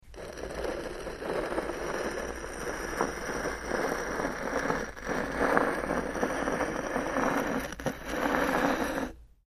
Scrape, Stone
StoneScrapesOnSmoo PE442202
Stone Scrapes; On Smooth Cement Slowly.